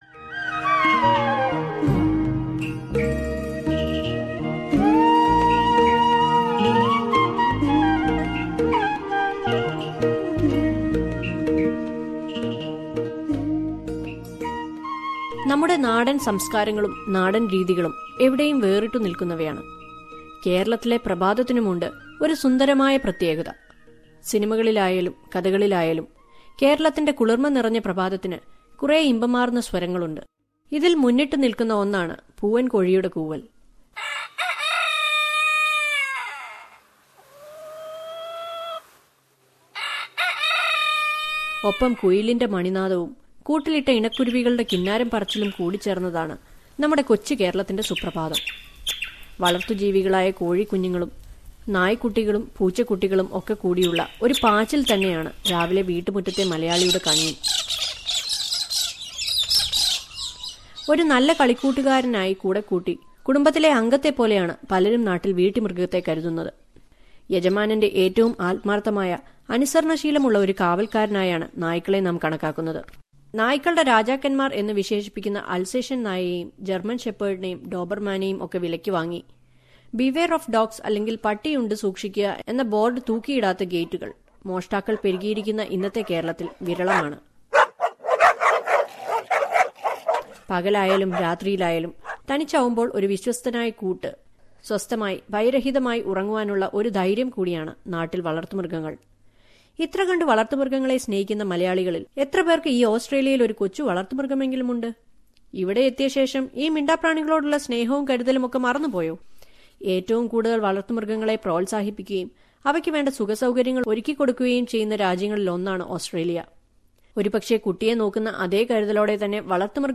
Listen to a report from Melbourne..